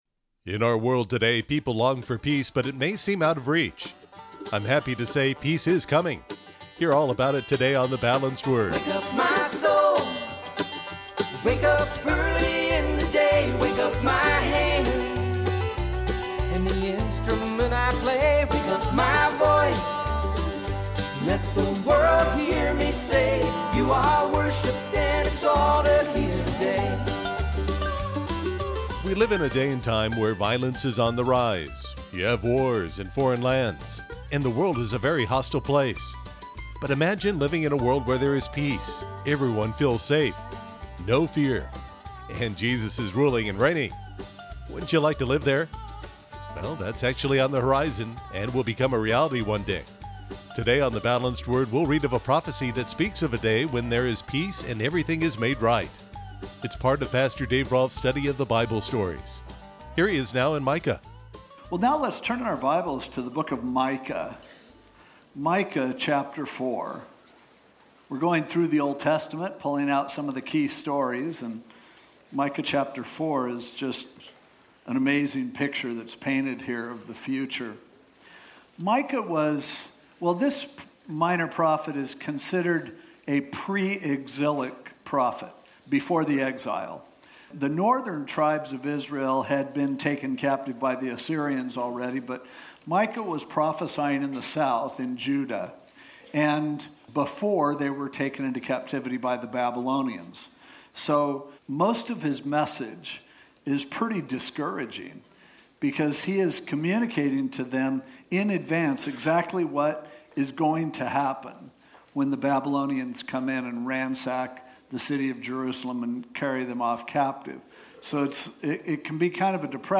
podcasted radio messages